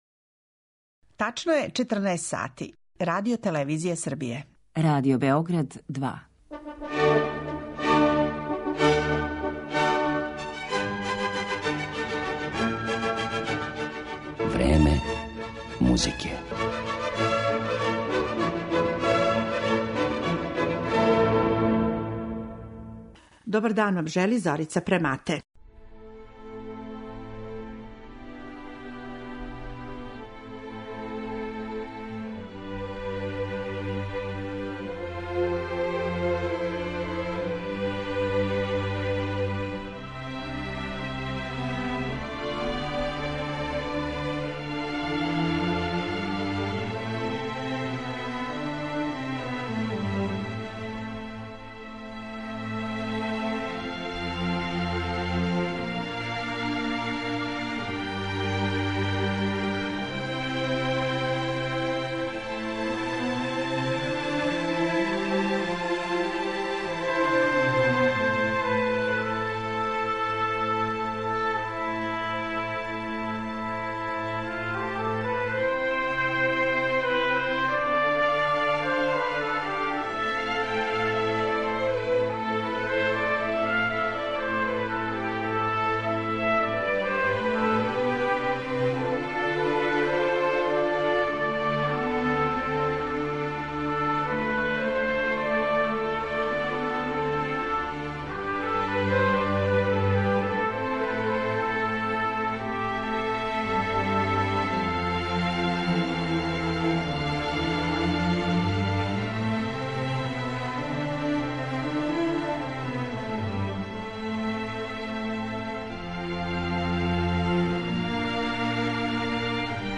Емисија ВРЕМЕ МУЗИКЕ посвећена је америчком композитору Алану Хованесу, необичном уметнику, филозофу, истраживачу музичких традиција других култура, једном од најплоднијих и најинтригантинијх музичких стваралаца целог 20. века кога је готово и премрежио својим дугим животом. Из његове пријемчиве и мелодичне музике